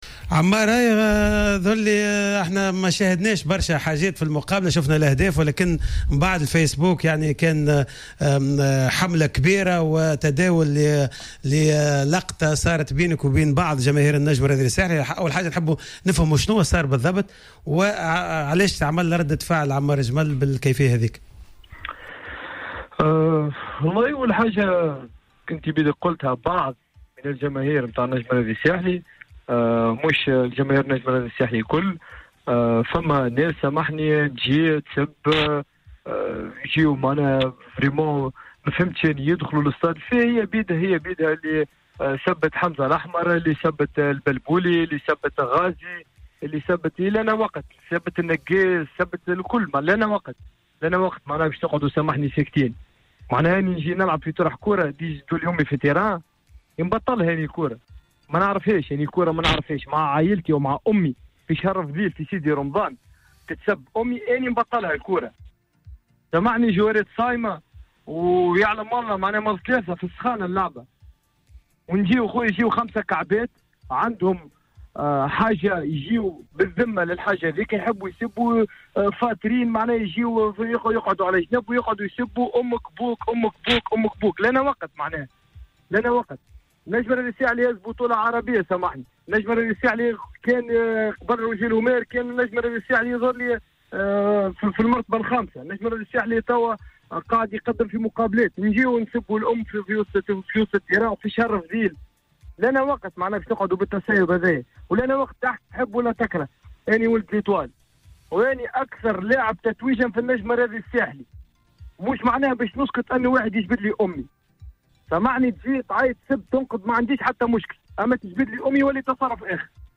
تدخل لاعب النجم الساحلي عمار الجمل في حصة "ستوديو الكان" على جوهرة أف أم لتوضيح حقيقة المناوشات التي حدثت بينه و بين جماهير النجم خلال المقابلة التي جمعت الفريق بإتحاد تطاوين اليوم الأحد 12 ماي 2019 بالملعب الأولمبي بسوسة لحساب الجولة 20 من البطولة.